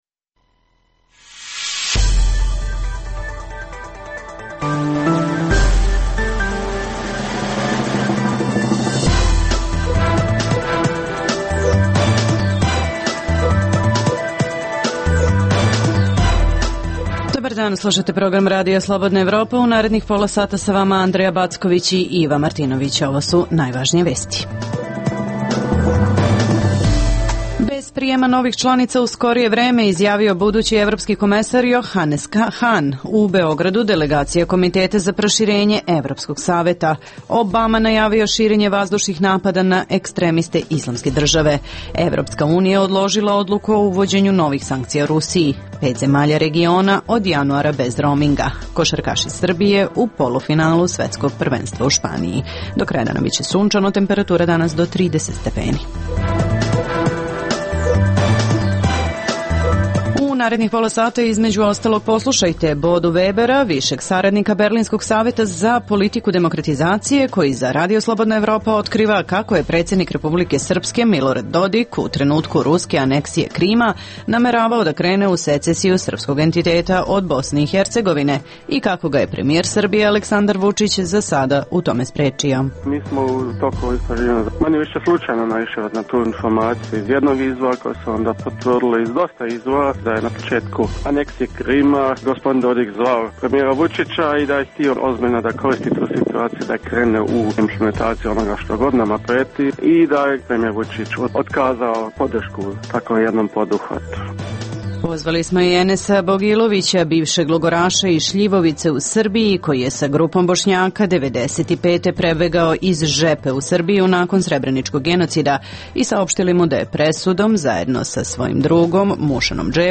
U emisiji poslušajte: - intervju